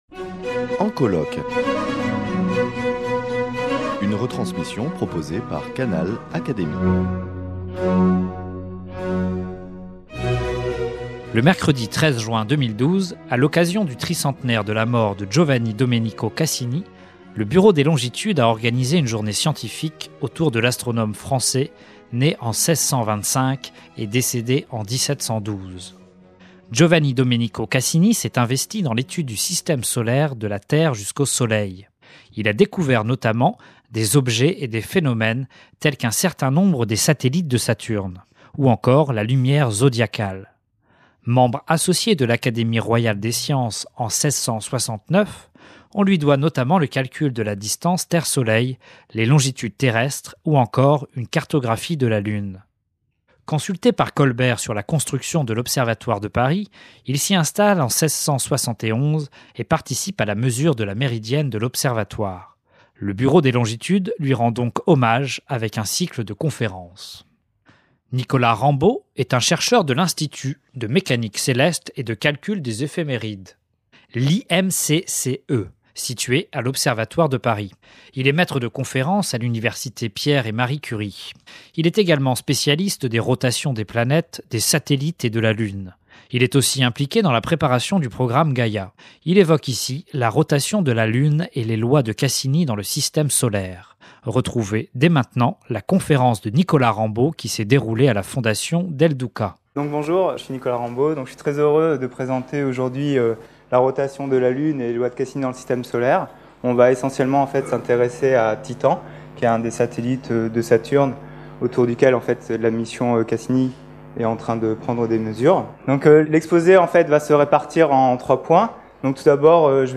A l’occasion du tricentenaire de la mort de Cassini, le Bureau des Longitudes a organisé , une journée scientifique autour de l’astronome français né en 1625 et décédé en 1712, académicien des sciences.
Son exposé aborde trois points :